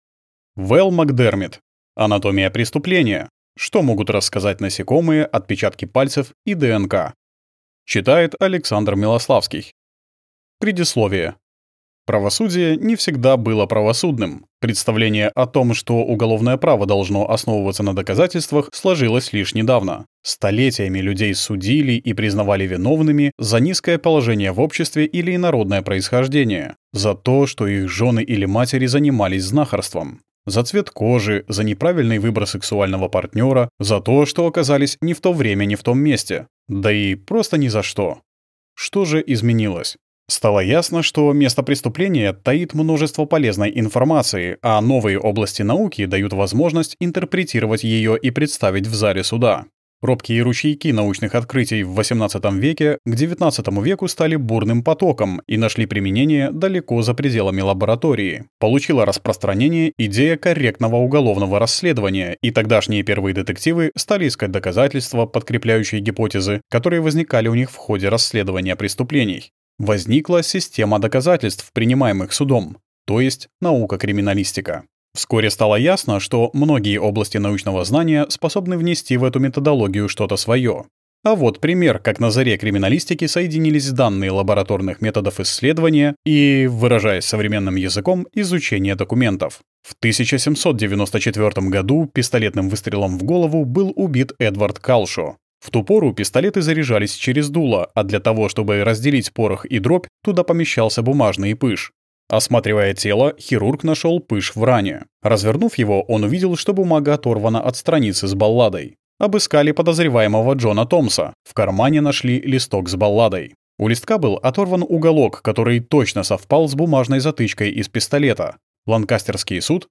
Аудиокнига Анатомия преступления: Что могут рассказать насекомые, отпечатки пальцев и ДНК | Библиотека аудиокниг